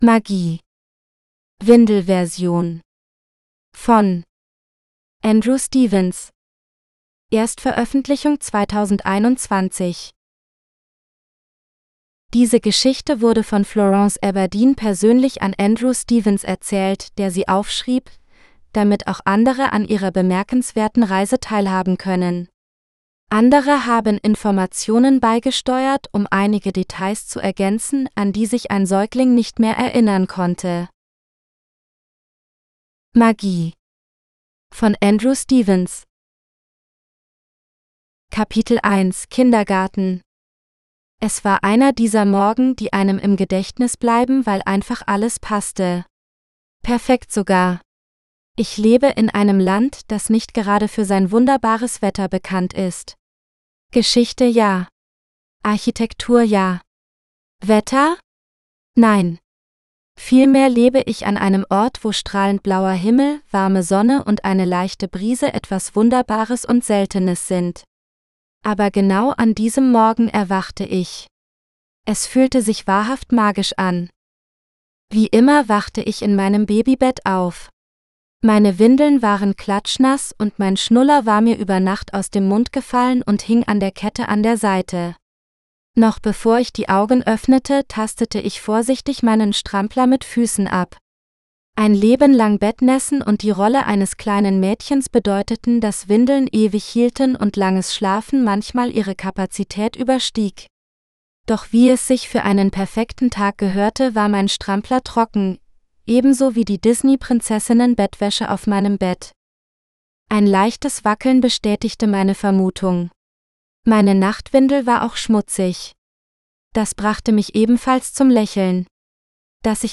Magic GERMAN (AUDIOBOOK – female): $US3.99